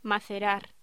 Locución: Macerar
voz